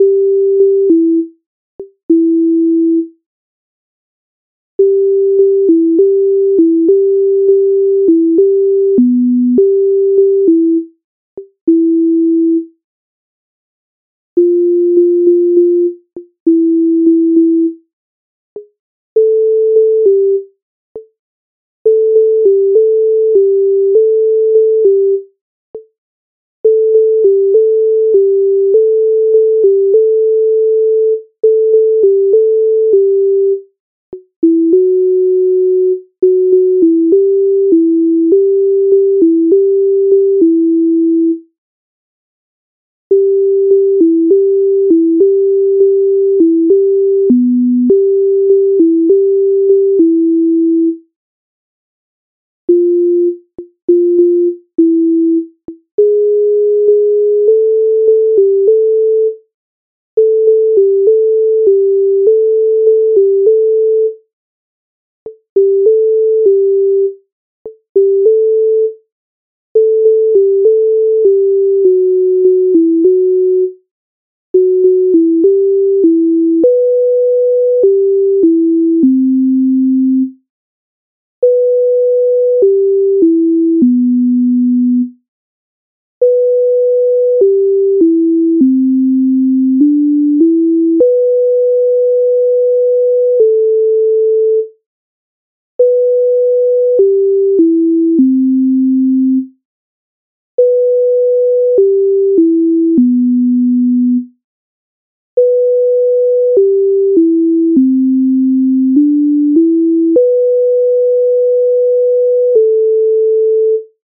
MIDI файл завантажено в тональності G-dur